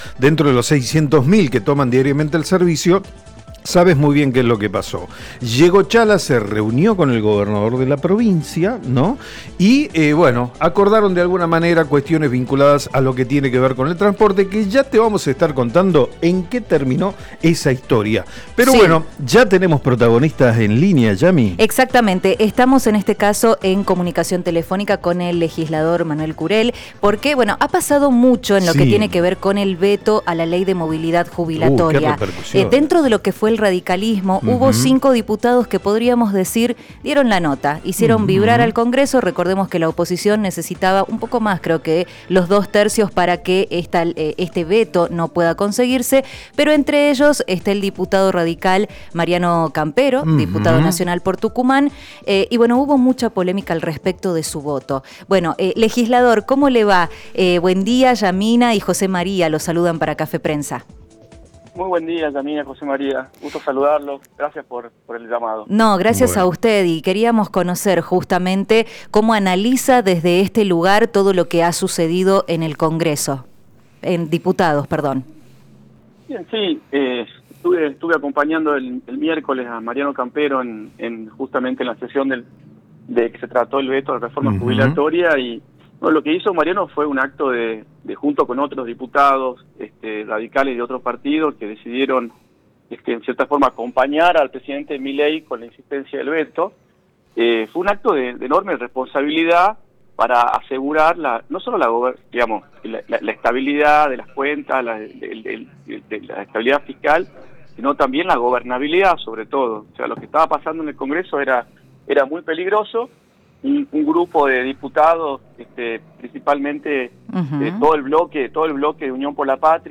El legislador provincial Manuel Courel, en diálogo telefónico con Café Prensa, se refirió al voto del diputado nacional Mariano Campero en favor del veto presidencial a la nueva Ley Jubilatoria.